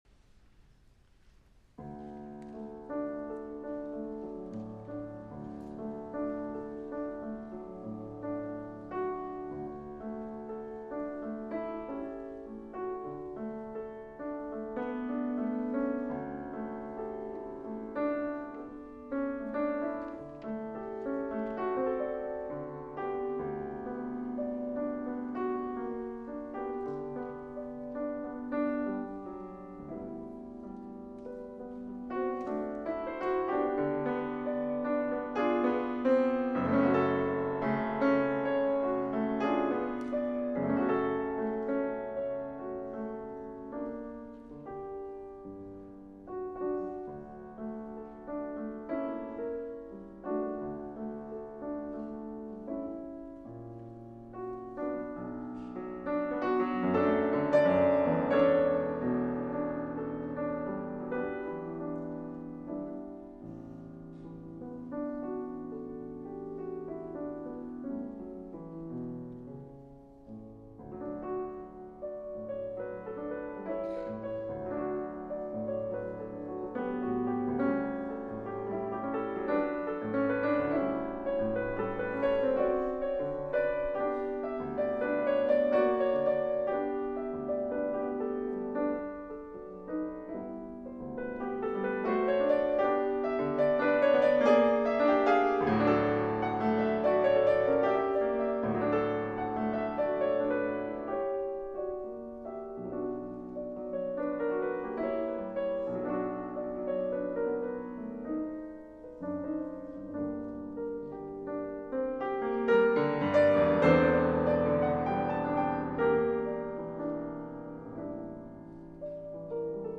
концертный пианист
С. Рахманинов. Прелюдия Ре мажор, op.23 N4
1.RachmaninovPrelude_D-dur.mp3